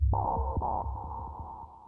synthFX02.wav